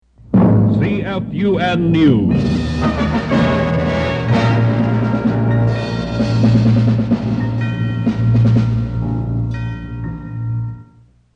CFUN NEWS